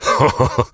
B_haha.ogg